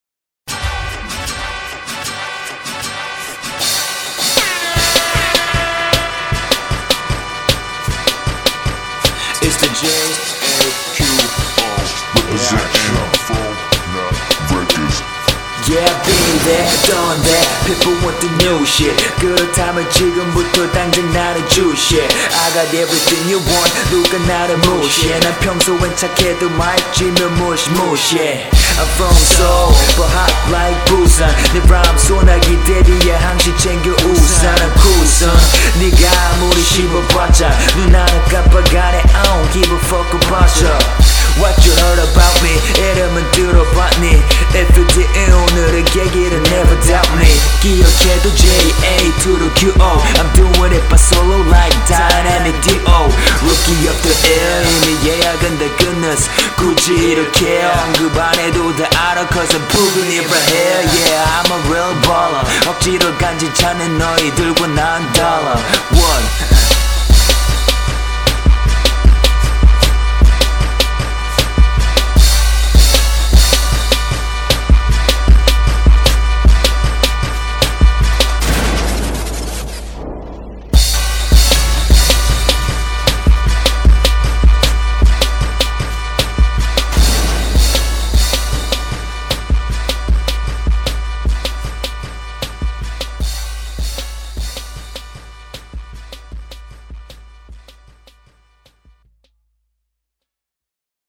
곡분위기랑 목소리랑 발음이랑 잘 어울려요